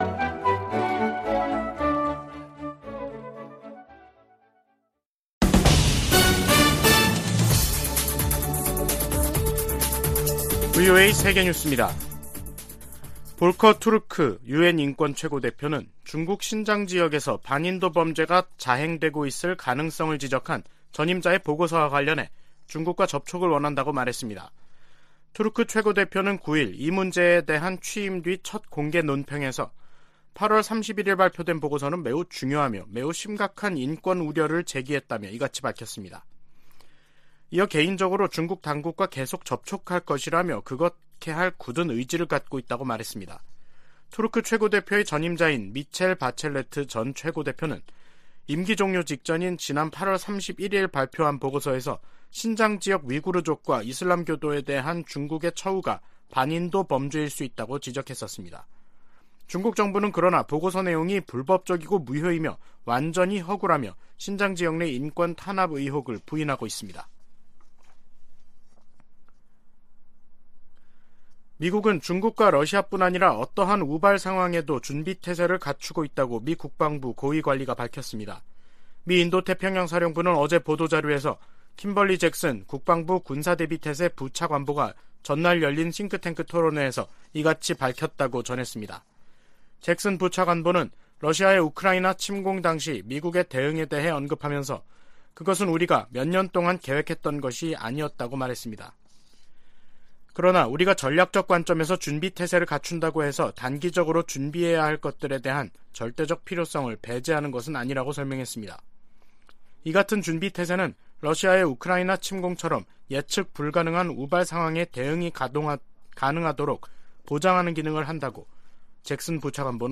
VOA 한국어 간판 뉴스 프로그램 '뉴스 투데이', 2022년 12월 9일 3부 방송입니다. 7차 핵실험 가능성 등 북한 김씨 정권의 핵 위협이 미국의 확장억지와 핵우산에 도전을 제기하고 있다고 백악관 고위관리가 지적했습니다. 국무부 대북특별대표가 중국 북핵 수석대표와의 화상회담에서 대북 제재 이행의 중요성을 강조했습니다.